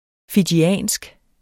Udtale [ fidjiˈæˀnsg ]